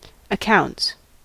Ääntäminen
Synonyymit book Ääntäminen : IPA : /ə.ˈkaʊnts/ US : IPA : [ə.'kaʊnts] Haettu sana löytyi näillä lähdekielillä: englanti Käännöksiä ei löytynyt valitulle kohdekielelle.